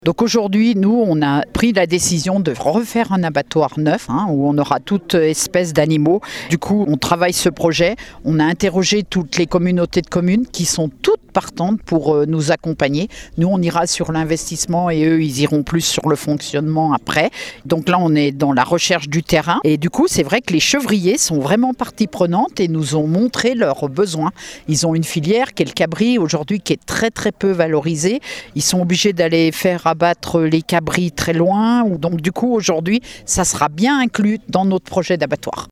Marie-Louise Donzel est vice-présidente du département en charge de l’agriculture.